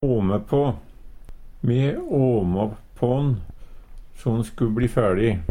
åme på - Numedalsmål (en-US)